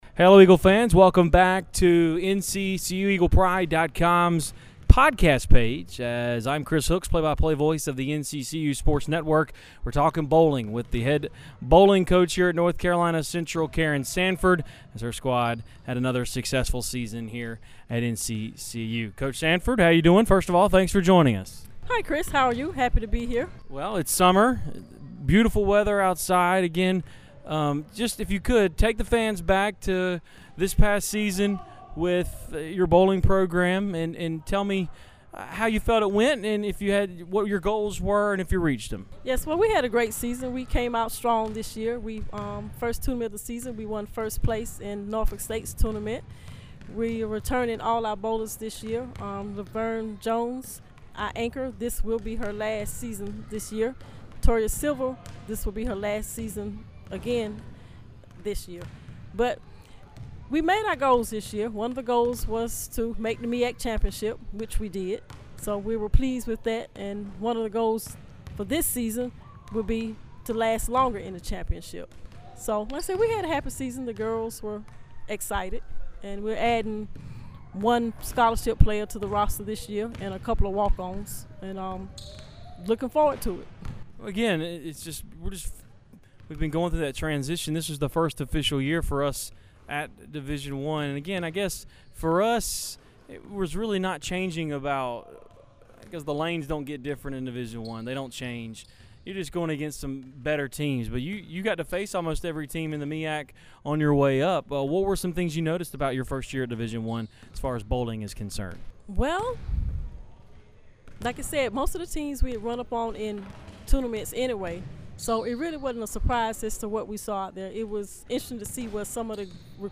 2012 Summer Interviews